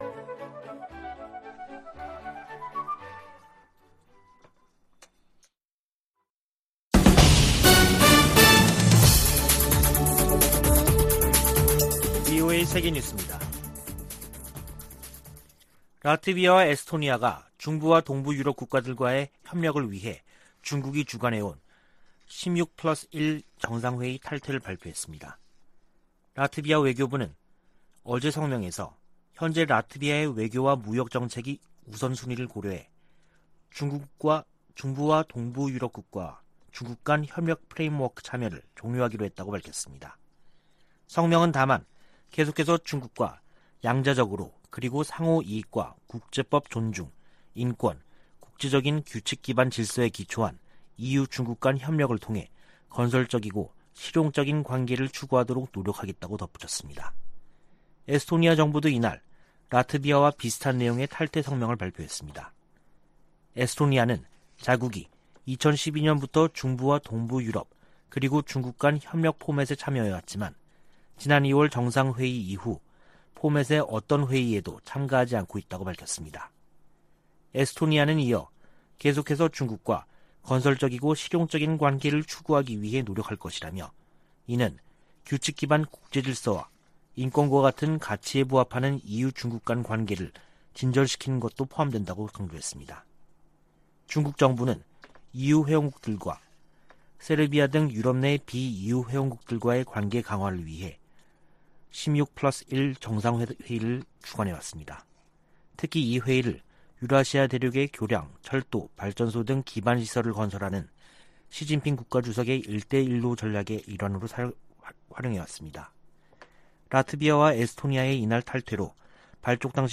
VOA 한국어 간판 뉴스 프로그램 '뉴스 투데이', 2022년 8월 12일 3부 방송입니다. 방한한 안토니우 구테흐스 유엔 사무총장은 북한의 완전하고 검증 가능하며 불가역적인 비핵화를 지지한다고 밝혔습니다. 윤석열 한국 대통령은 에드 마키 미 상원 동아태소위원장을 접견하고 동맹 강화와 한일 경제 협력에 애써 준 데 대해 감사의 뜻을 전했습니다. 미 국무부는 ‘코로나 방역전 승리’를 주장한 북한이 국제사회 백신 지원 제안을 수용해야 한다고 강조했습니다.